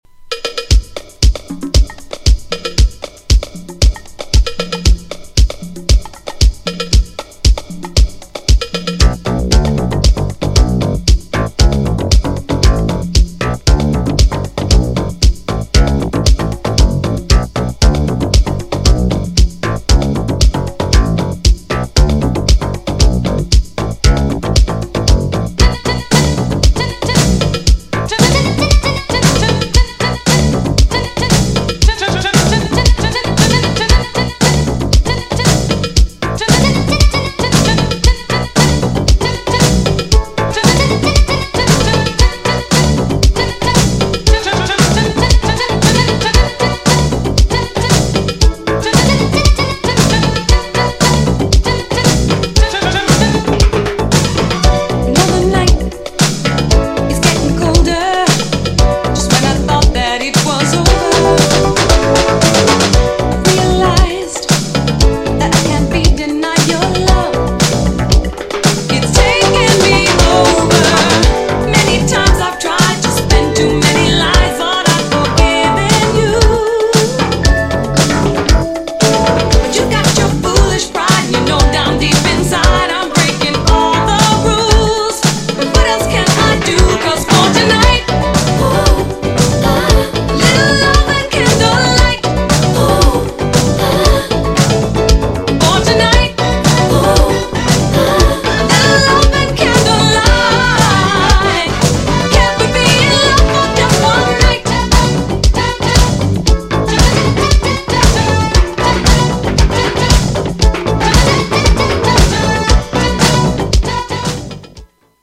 GENRE Dance Classic
BPM 126〜130BPM